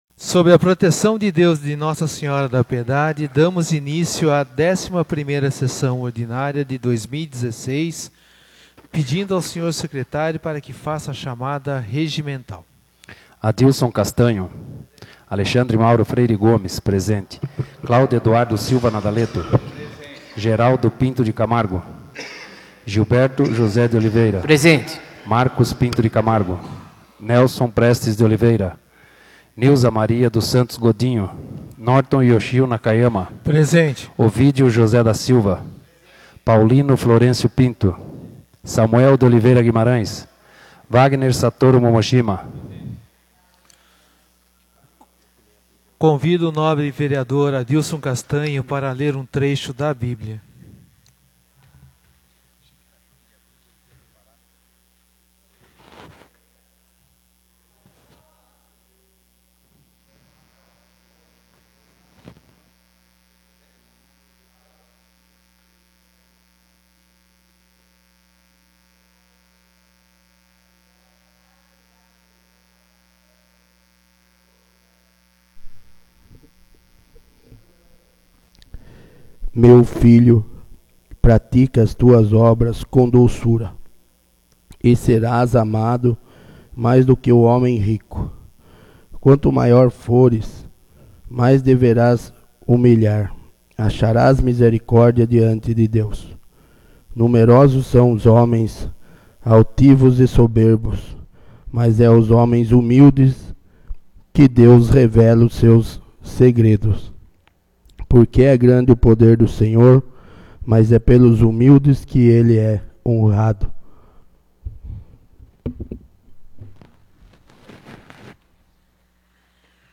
11ª Sessão Ordinária de 2016 — Câmara Municipal de Piedade